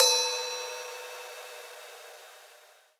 Ride_6.wav